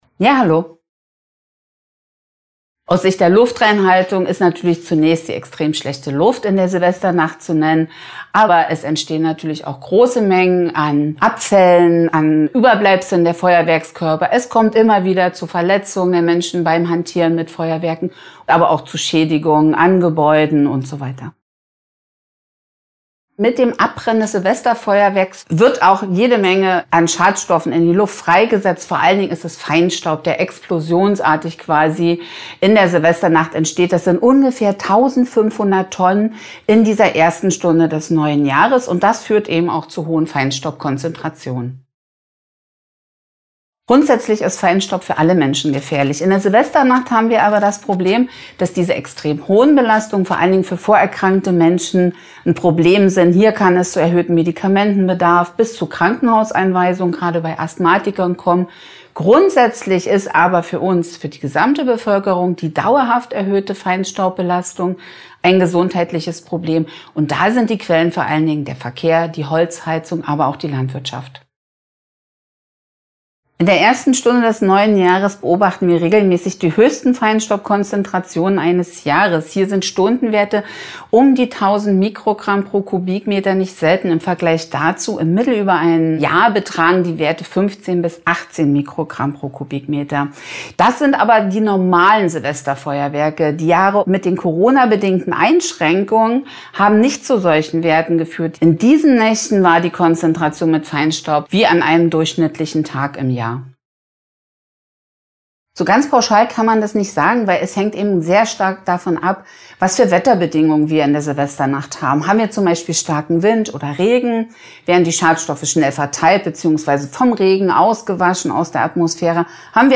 Interview: 2:30 Minuten